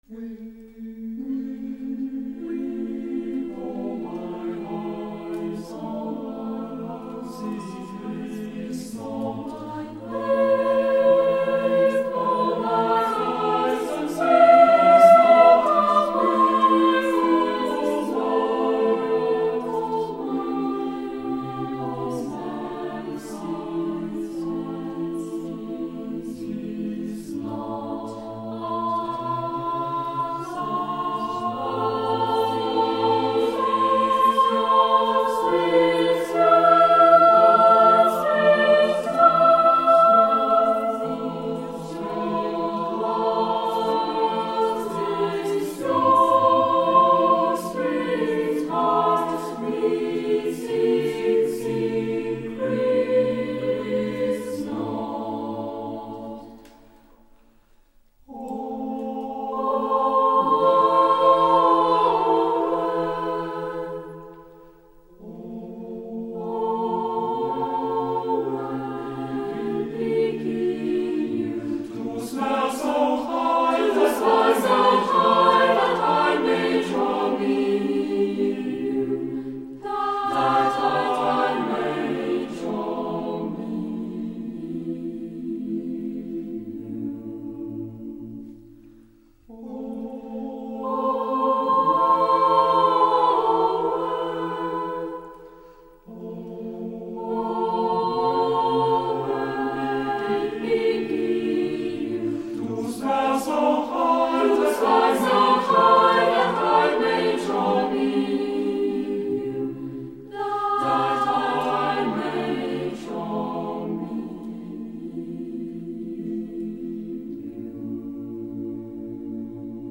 录音地址:石碑胡同中国唱片社录音棚
【欧洲牧歌部分】